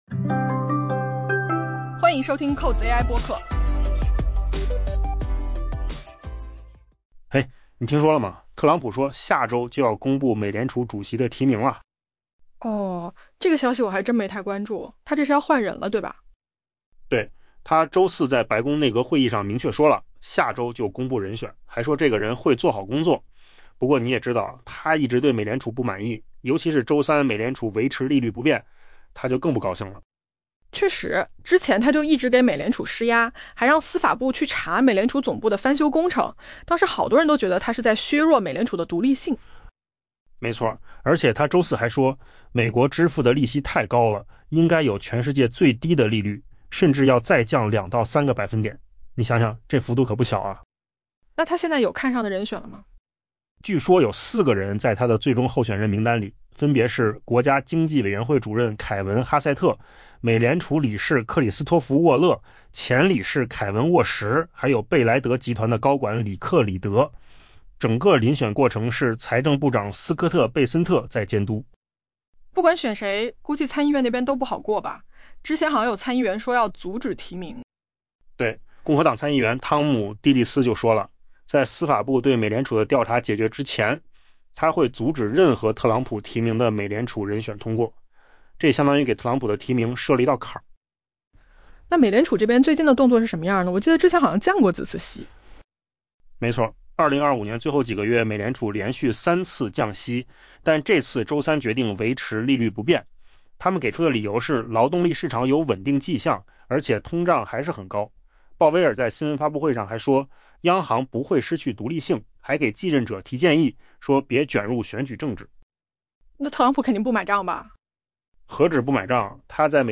AI播客：换个方式听新闻 下载mp3 音频由扣子空间生成 美国总统特朗普最新表示，将于当地时间明天早上（北京时间今晚）宣布美联储主席人选。